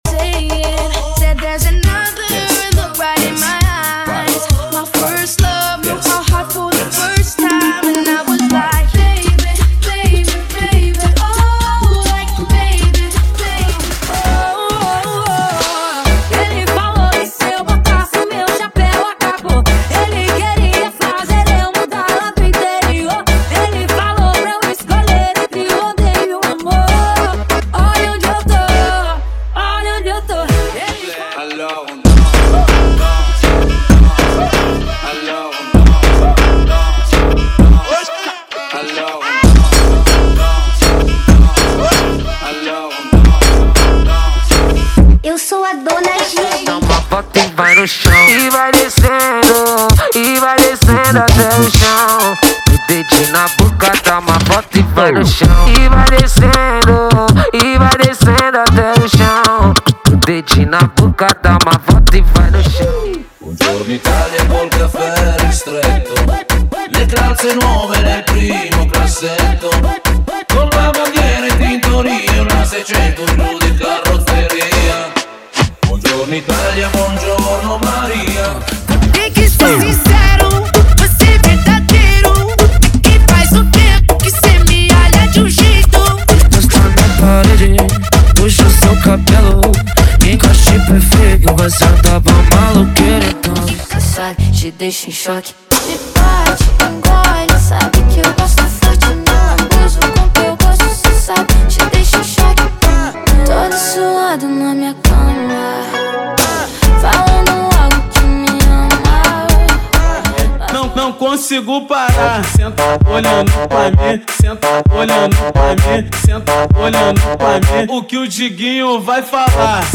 • Funk Light e Funk Remix = 105 Músicas
• Sem Vinhetas
• Em Alta Qualidade